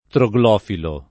[ tro g l 0 filo ]